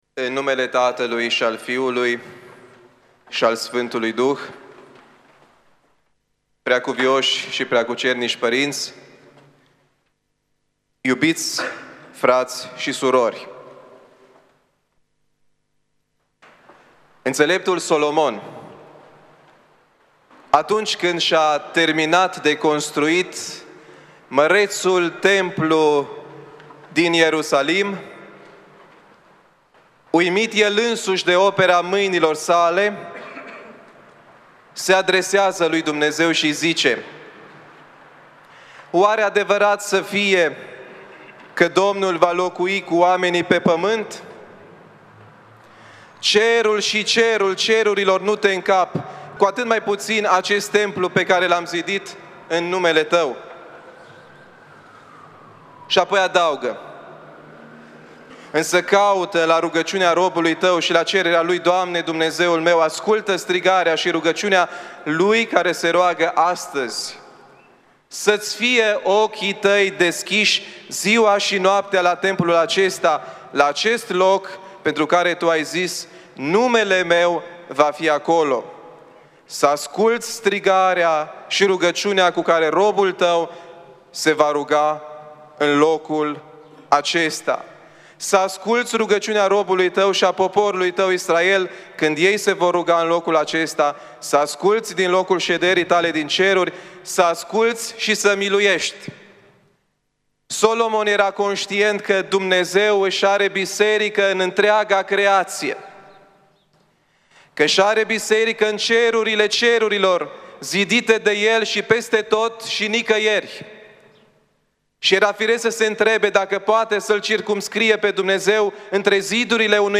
În Duminica a II-a din Postul Mare (a Sfântului Grigorie Palama), 15 martie 2020, Preasfințitul Părinte Benedict Bistrițeanul, Episcopul-vicar al Arhiepiscopiei Vadului, Feleacului și Clujului, a liturghisit și a predicat la Catedrala Mitropolitană din Cluj-Napoca.
După citirea evangheliei duminicale, Preasfințitul Părinte Benedict Bistrițeanul a rostit un cuvânt de învățătură, vorbind pe tema Biserica – Spital de campanie.